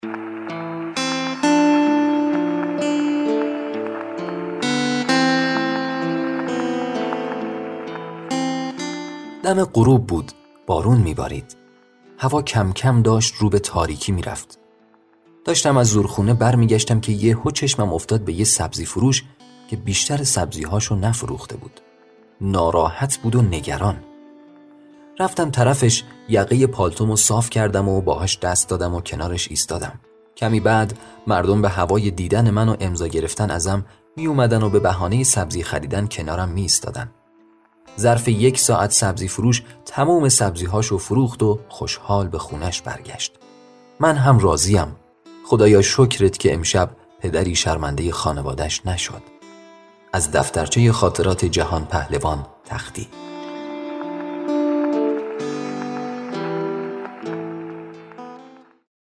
غلامرضا تختی - چرا به او جهان پهلوان می گفتند؟ - داستان صوتی کوتاه - میقات مدیا